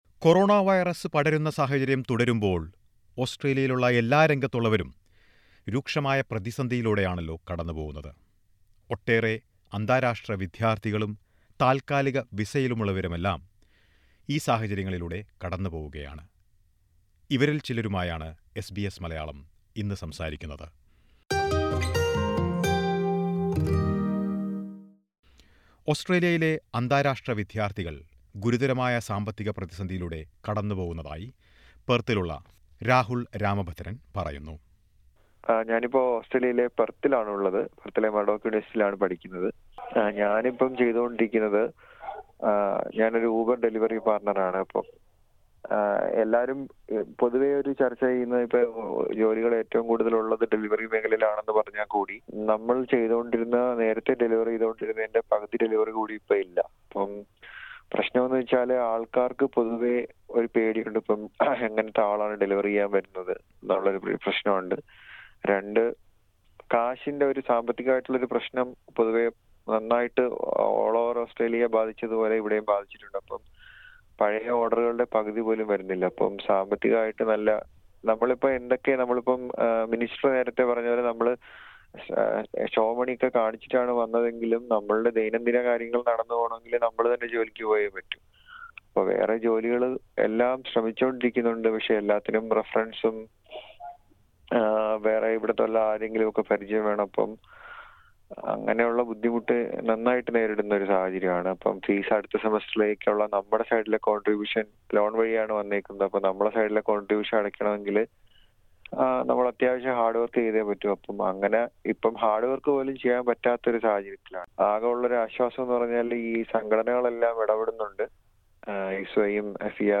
താൽക്കാലിക വിസയിലുള്ളവരും സ്റ്റുഡന്റ് വിസയിലുള്ളവരും ജോലിയില്ലെങ്കിൽ മടങ്ങിപ്പോകണമെന്ന സർക്കാരിന്റെ നിർദ്ദേശം നിരവധി മലയാളികളെയാണ് ആശങ്കയിലാക്കിയിരിക്കുന്നത്. ഇവരുടെ ആശങ്കകൾ എസ് ബി എസ് മലയാളവുമായി പങ്കുവയ്ക്കുന്നത് കേൾക്കാം.
ഓസ്‌ട്രേലിയയിലുള്ള ചില അന്തരാഷ്ട്ര വിദ്യാർത്ഥികളോട് എസ് ബി എസ് മലയാളം സംസാരിച്ചു.